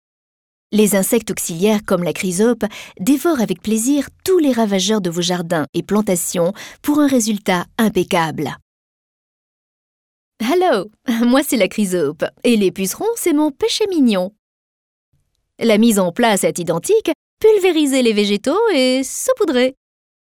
Voix jeune et fraiche mais pouvant être aussi plus mature ou plus sensuelle.
Sprechprobe: Industrie (Muttersprache):